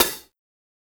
Closed Hats
HIHAT_INJECTION.wav